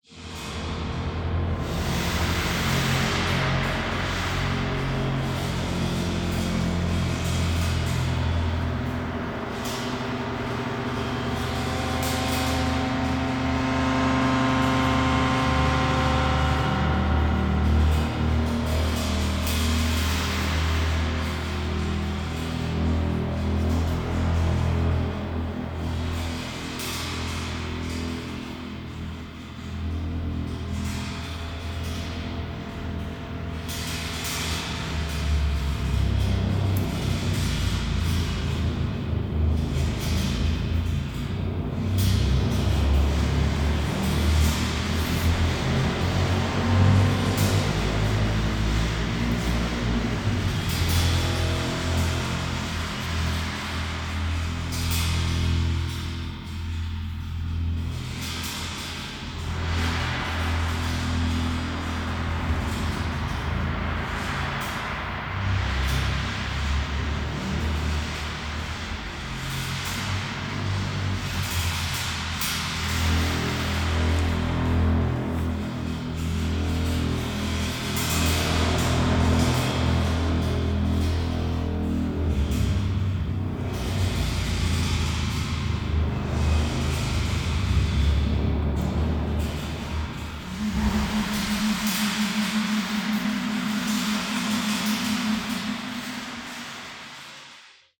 Photo of the Klangwerkstatt Festival, me on the left with the Hydra & two snippets:
my “solo” of a drone and inharmonic spectra, matching a cymbal accompaniment